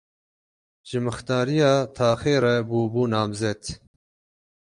/nɑːmˈzɛt/